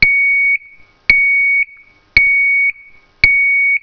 ZUMBADOR - SONIDO CONTINUO O INTERMITENTE
75 a 92dB
intermitente 24v
intermitente 24v.wav